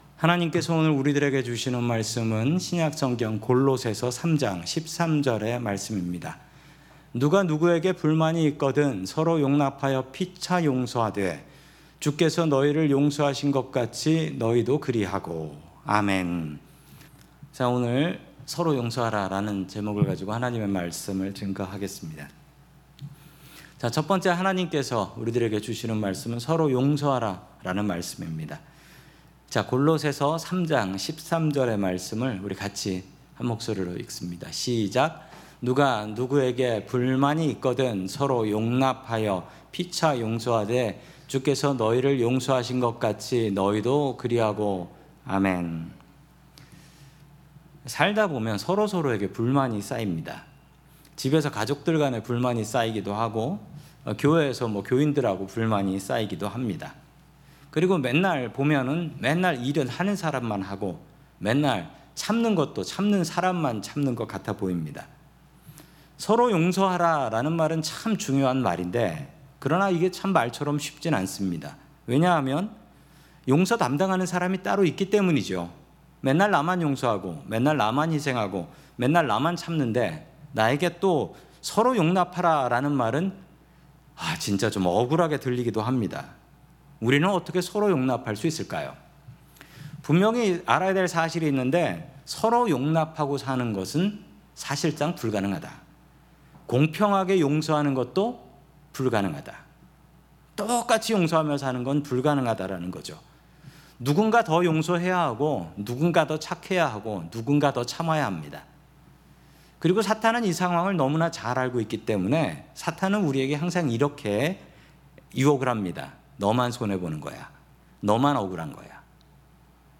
샌프란시스코 은혜장로교회 설교방송